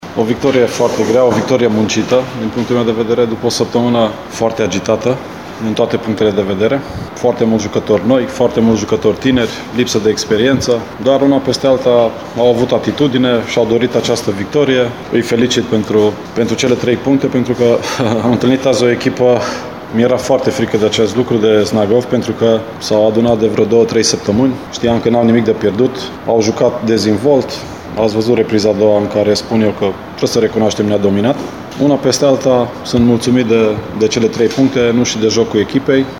După meci au vorbit cei doi antrenori